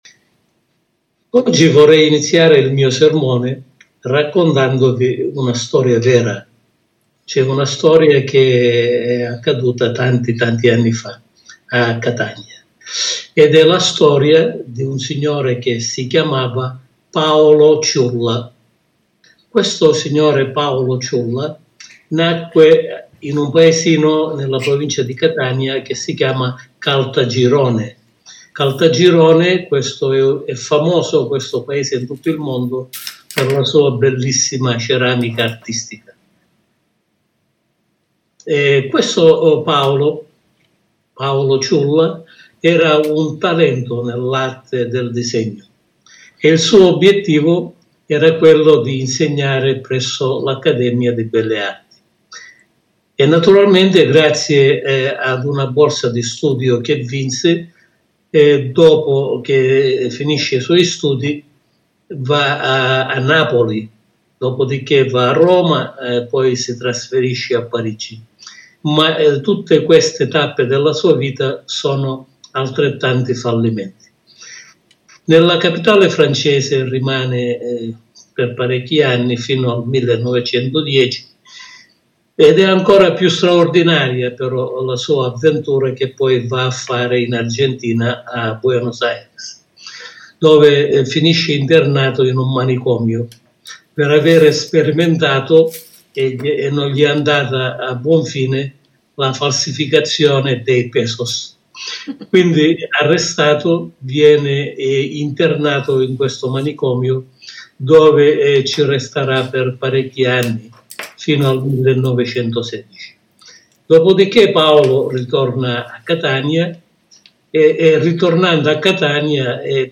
Sermone pastorale